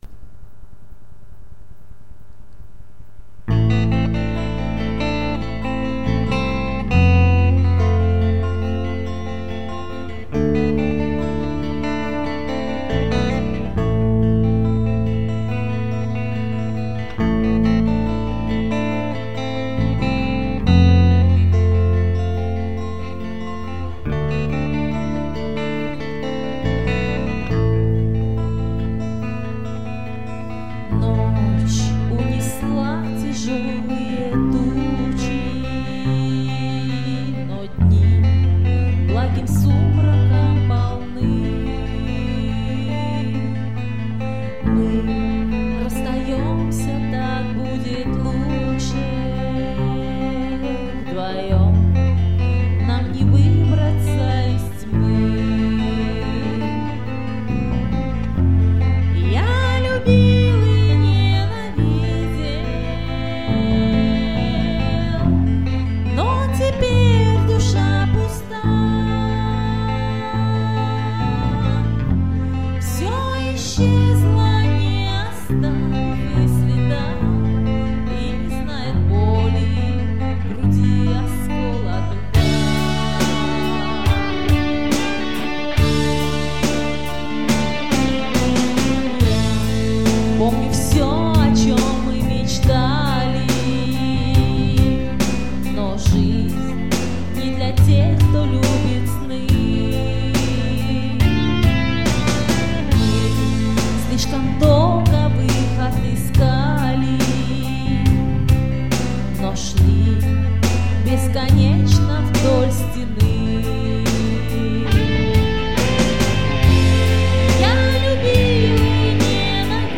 песня роковая, а рока в ней нет!